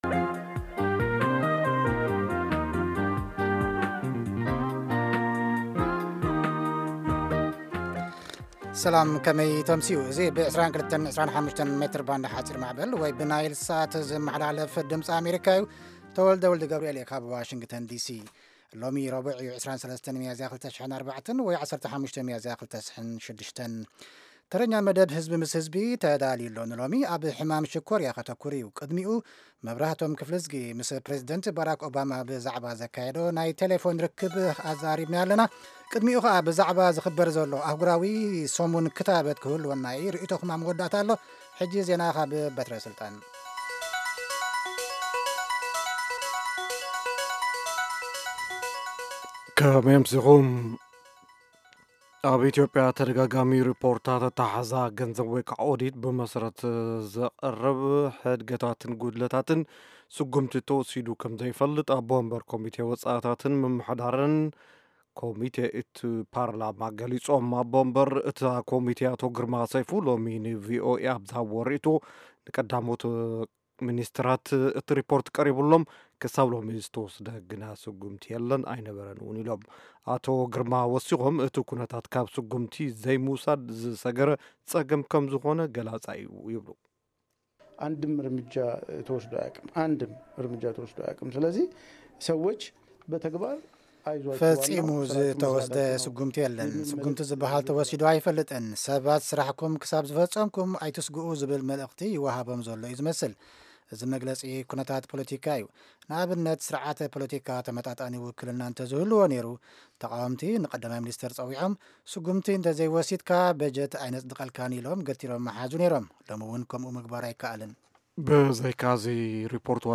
Half-hour broadcasts in Tigrigna of news, interviews with newsmakers, features about culture, health, youth, politics, agriculture, development and sports on Monday through Friday evenings at 10:00 in Ethiopia and Eritrea.